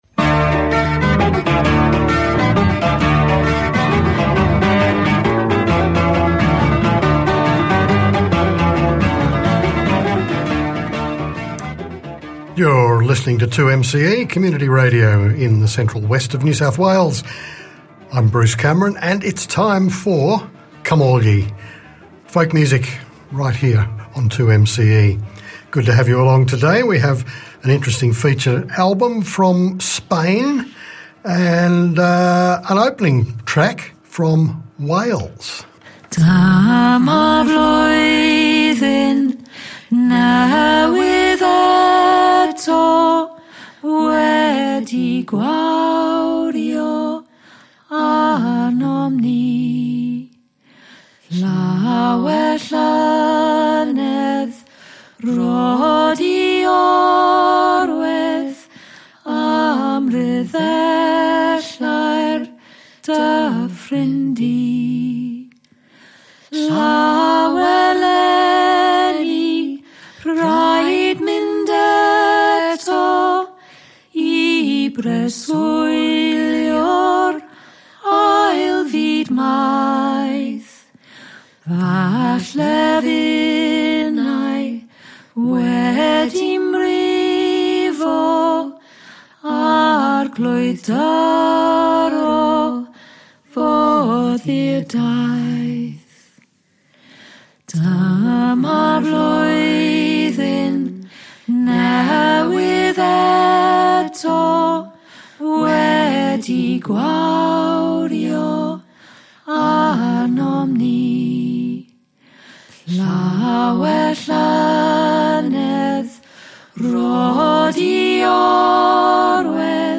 guitars, cittern, mandolin
flutes, whistle, percussion